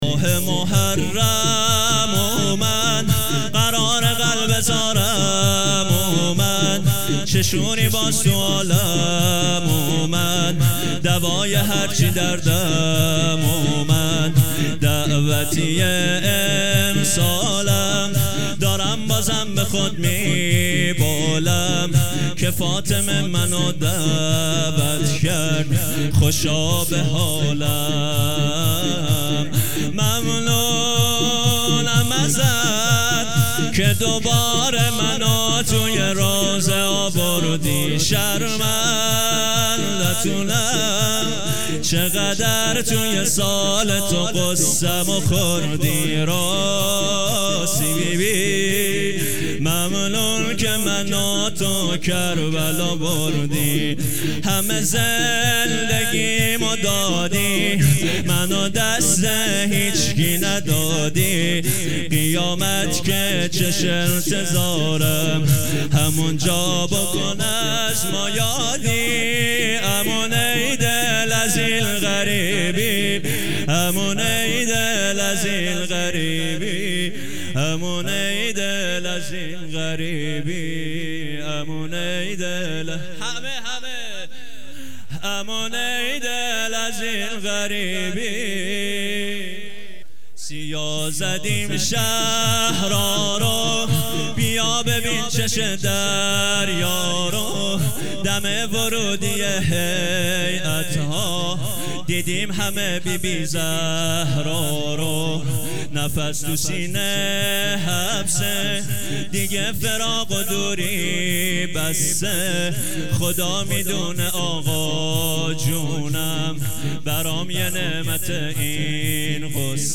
شور ( ماه محرم اومد قرارقلب زارم اومد )
شب دوم محرم 1440